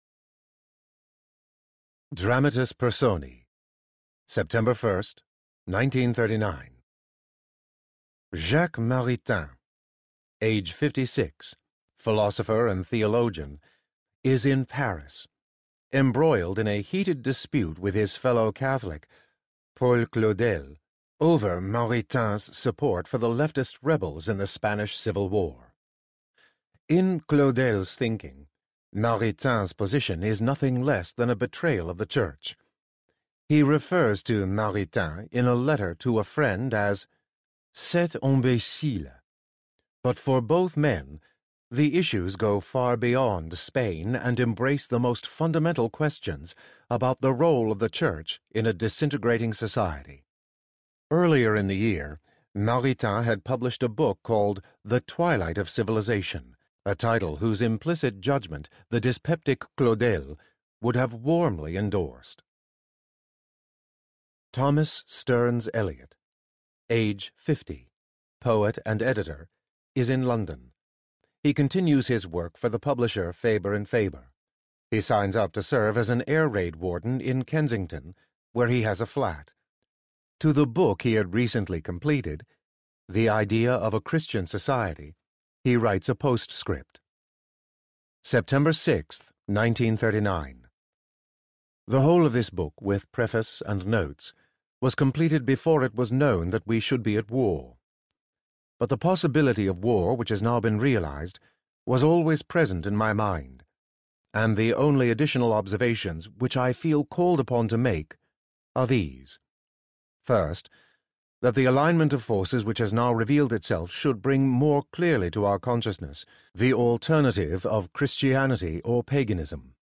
The Year of Our Lord 1943 Audiobook
Narrator
8.6 Hrs. – Unabridged